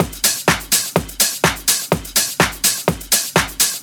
• flashy techno top loop.wav
flashy_techno_top_loop_jHF.wav